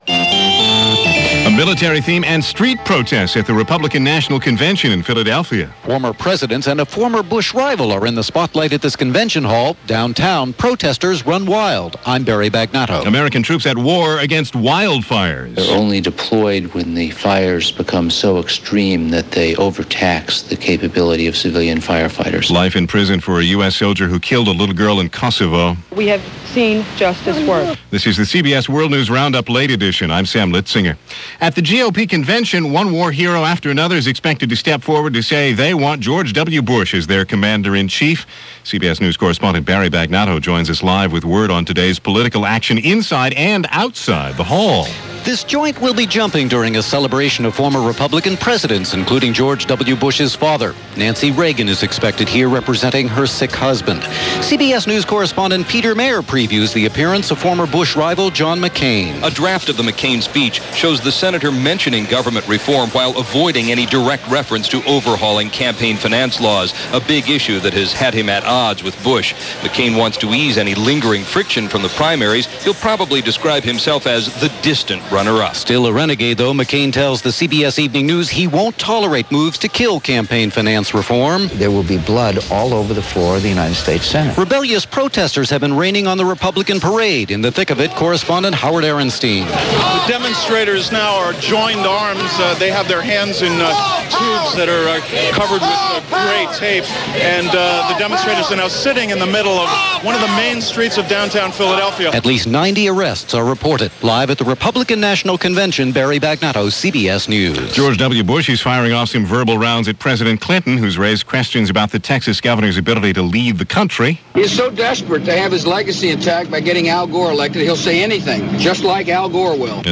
And that’s a small slice of what happened, this August 1, 2000 as reported by The CBS World News Roundup: Late Edition.